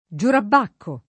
giurabbacco [ J urabb # kko ] escl.